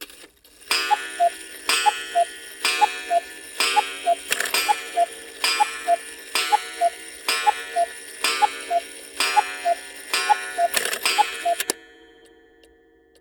cuckoo-clock-12.wav